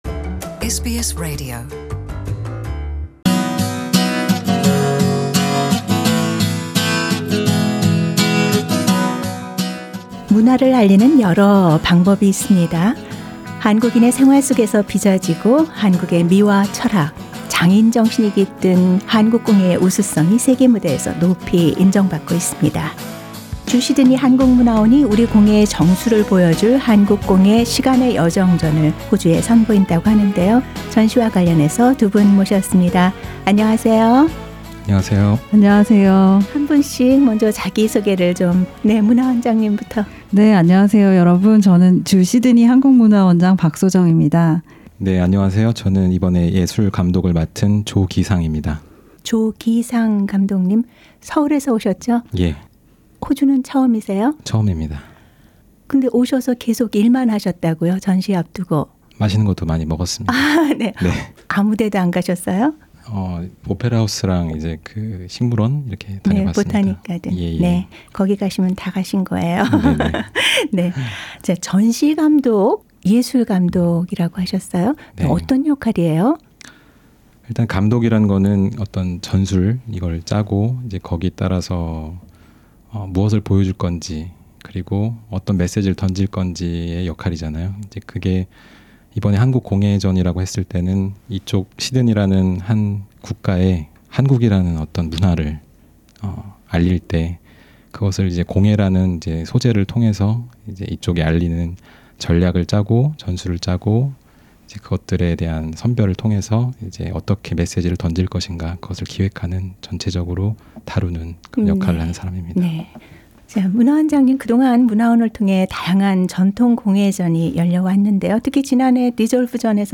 Exhibition information 26 July – 14 September 2018 Korean Cultural Centre Australia Gallery [Full interview is available on audio news.]